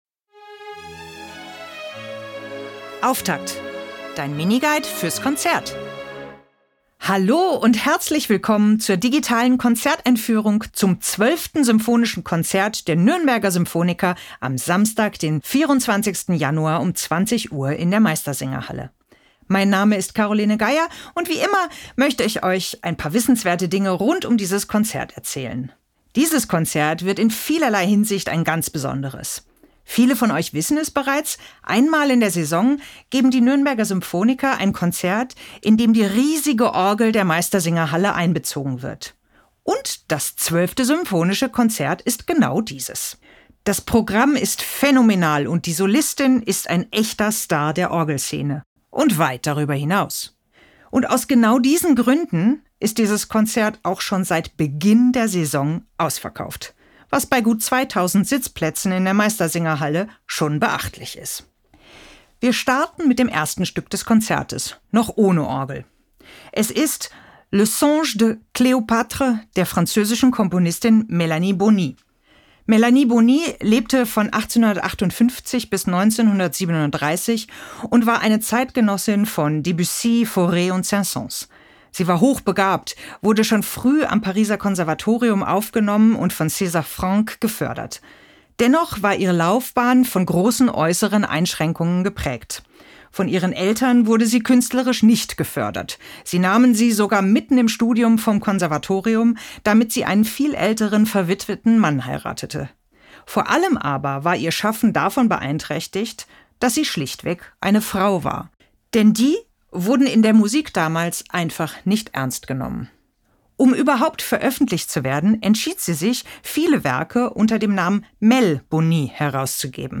Musik Jingle: Serenade für Streicher, 2. Satz – Tempo di Valse‘